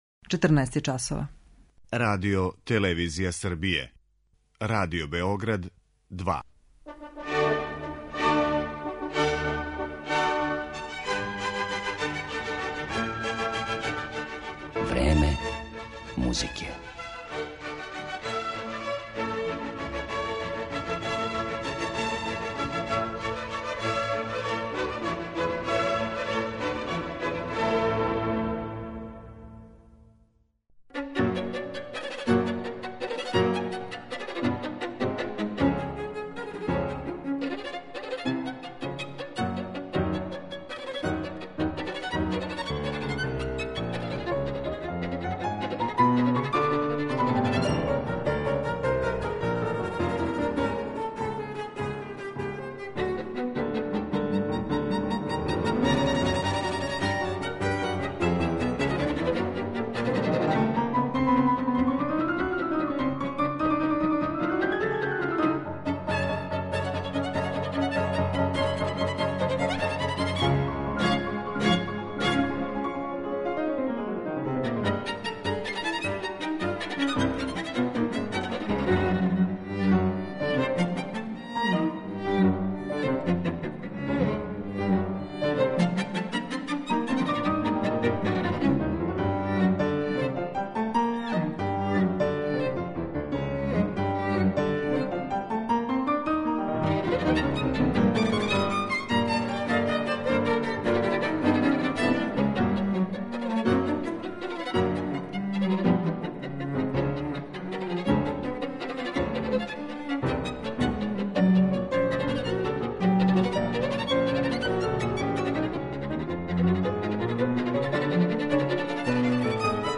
камерни ансамбл